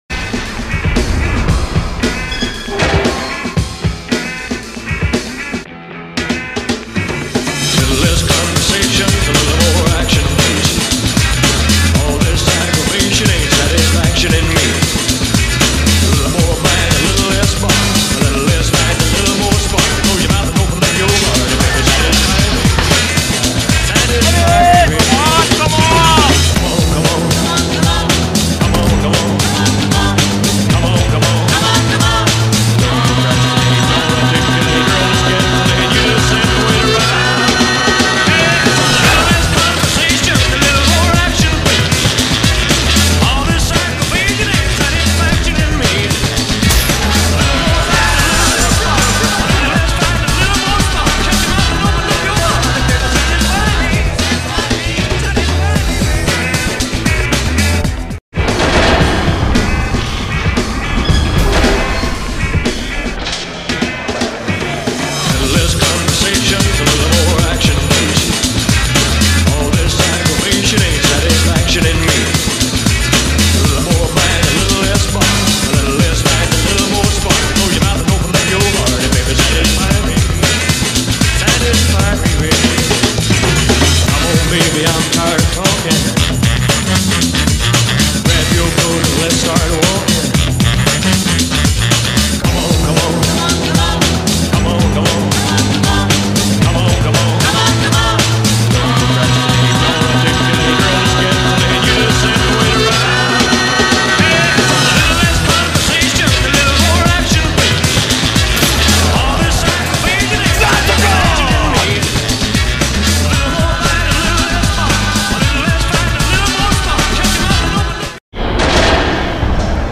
A trilha sonora icônica